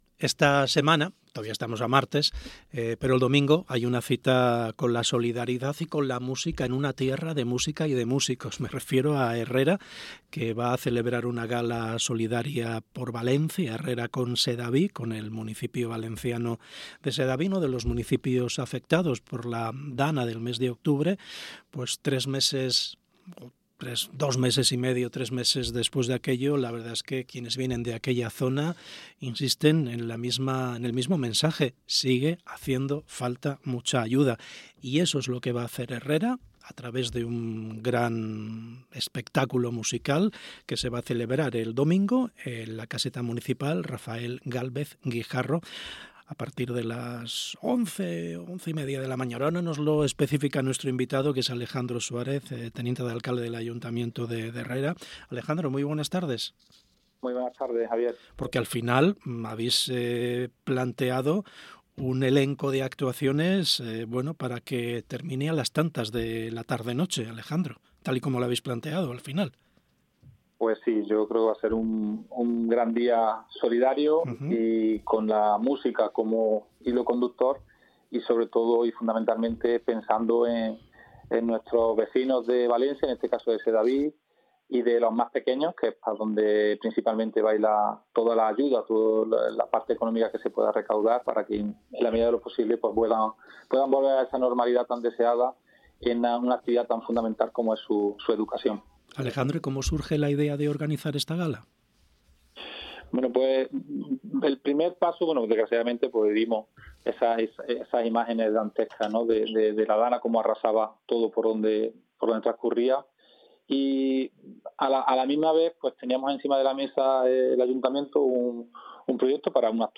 Herrera Con Sedaví. Entrevista a Alejandro Suarez
Alejandro Suarez, primer teniente de alcalde de Herrera y miembro del comité organizador ha pasado por el programa Hoy por Hoy SER Andalucía Centro.